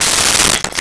Glass Break 01
Glass Break 01.wav